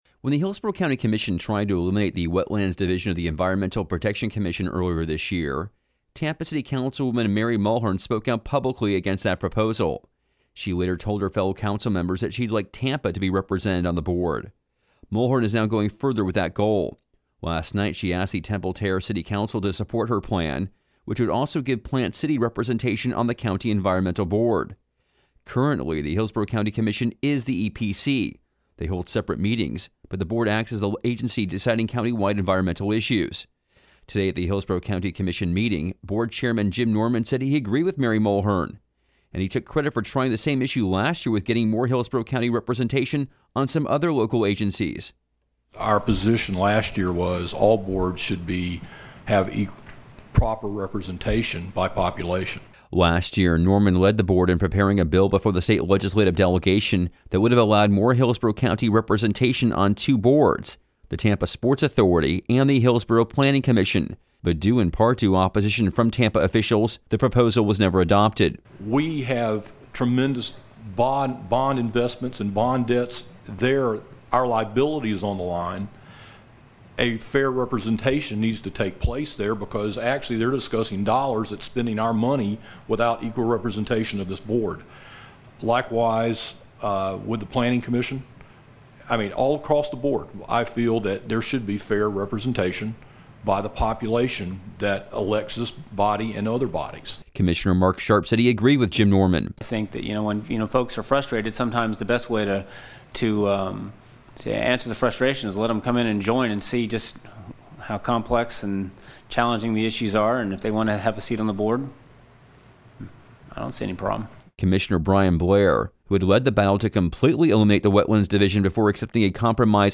interview on WMNF.